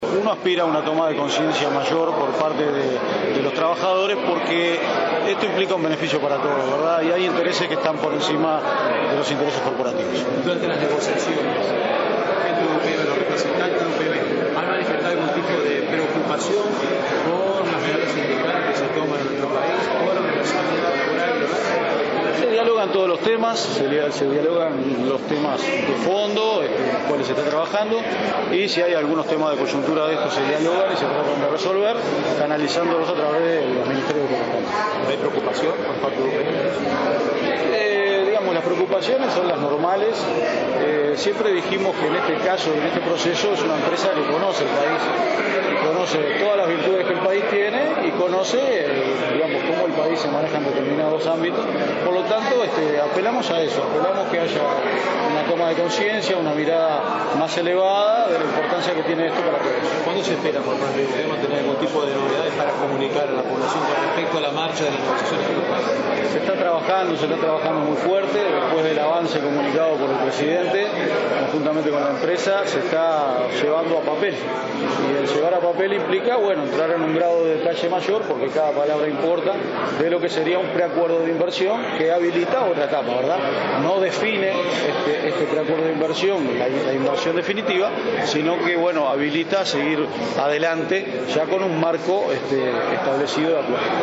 “Aspiramos a que haya una toma de conciencia mayor de parte de los trabajadores, porque implica un beneficio para todos, y hay intereses que están por encima de los corporativos,” afirmó al director de la Oficina de Planeamiento y Presupuesto, Álvaro García, al ser consultado por las medidas tomadas por trabajadores de AFE. Señaló que se avanza con UPM para pasar a una nueva etapa del proyecto.